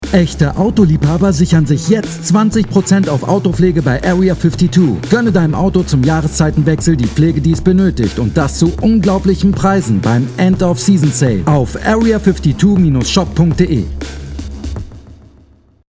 dunkel, sonor, souverän
Mittel minus (25-45)
Commercial (Werbung)